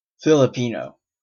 FIL-ə-PEE-noh;[1] Wikang Filipino, [ˈwikɐŋ filiˈpino̞]) is the national language of the Philippines, the main lingua franca, and one of the two official languages of the country, along with English.[2] It is only a de facto and not a de jure standardized form of the Tagalog language,[3] as spoken and written in Metro Manila, the National Capital Region, and in other urban centers of the archipelago.[4] The 1987 Constitution mandates that Filipino be further enriched and developed by the other languages of the Philippines.[5]
En-ca-Filipino.oga.mp3